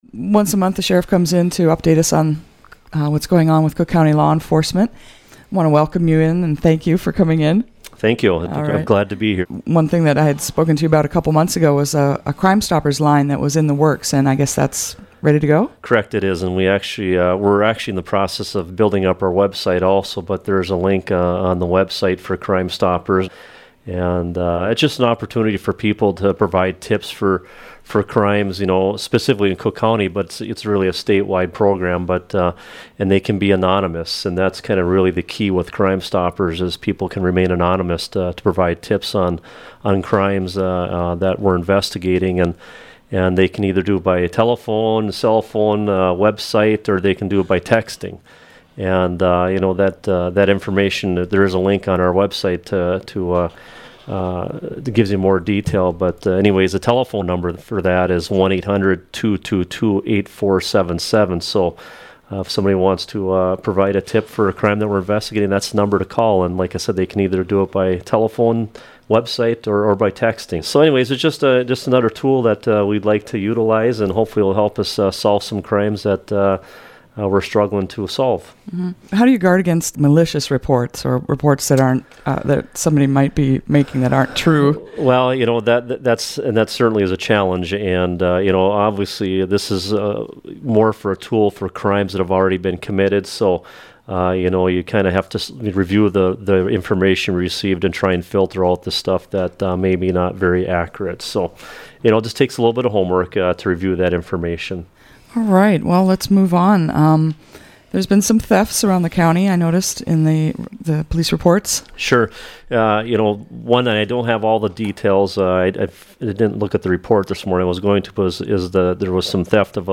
As part of that effort, we interview local leaders on a regular basis.